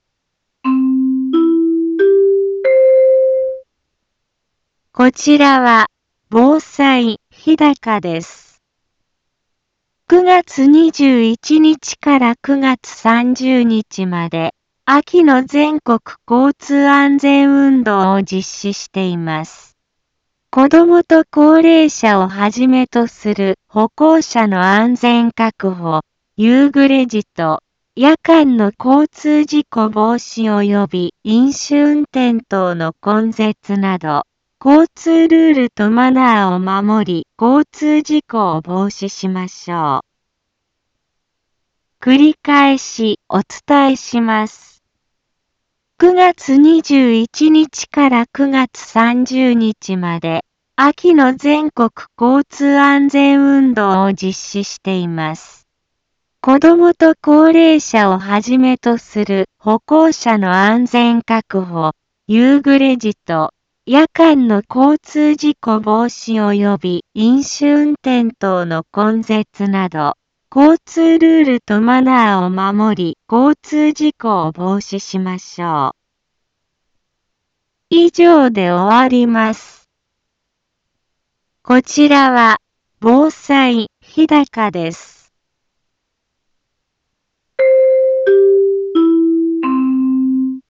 一般放送情報
BO-SAI navi Back Home 一般放送情報 音声放送 再生 一般放送情報 登録日時：2024-09-24 10:03:14 タイトル：交通安全のお知らせ インフォメーション： 9月21日から9月30日まで「秋の全国交通安全運動」を実施しています。